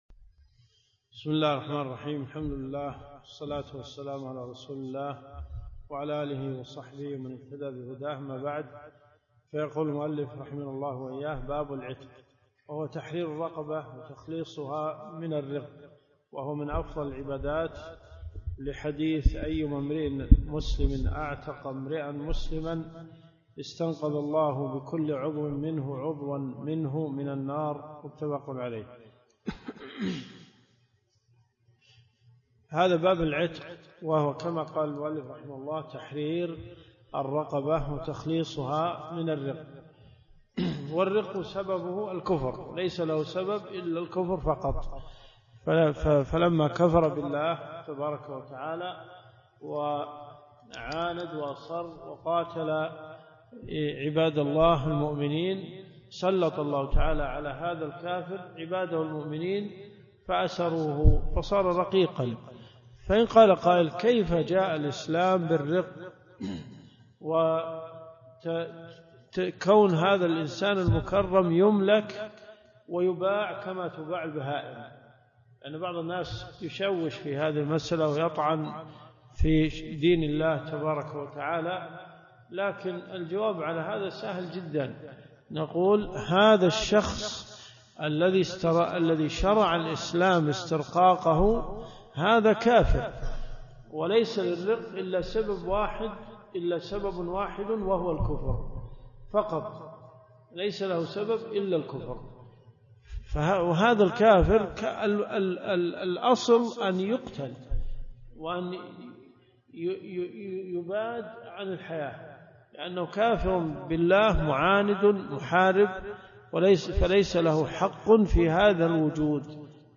الدروس الشرعية
المدينة المنورة . جامع البلوي